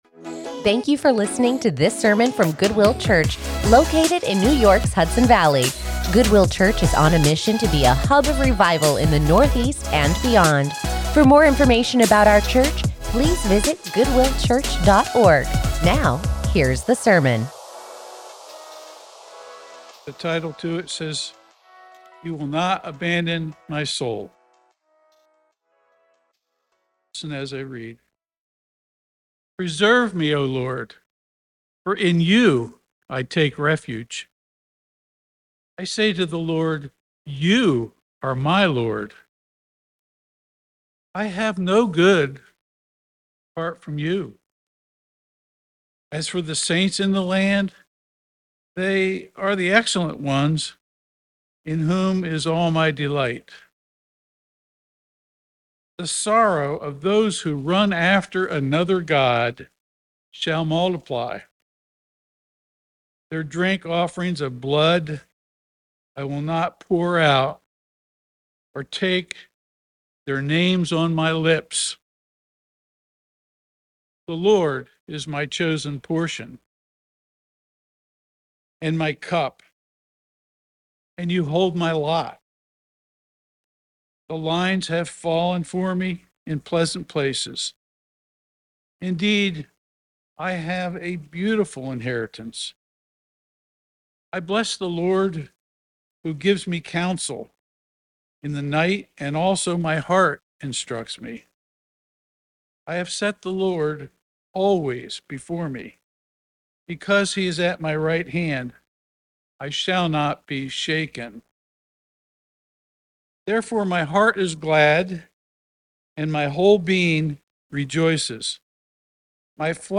Join us in the study of God's Word as we continue our sermon series